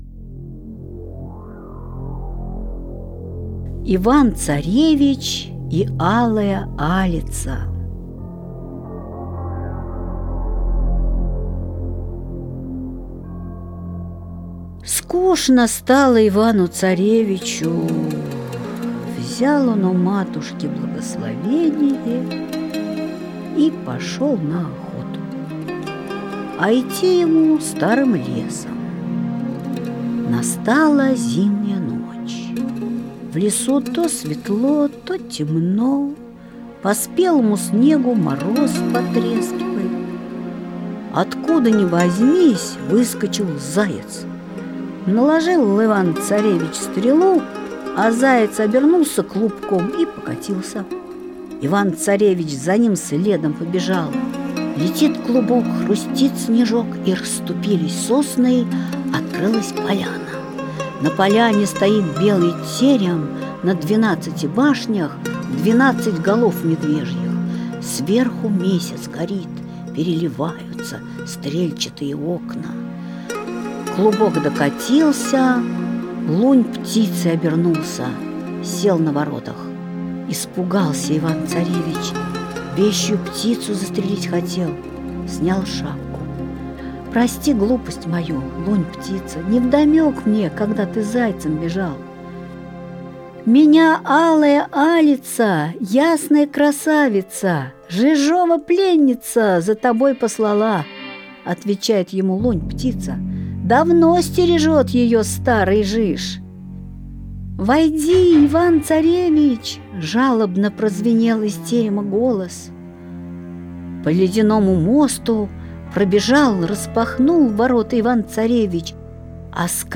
Иван-царевич и Алая-Алица – Толстой А.Н. (аудиоверсия)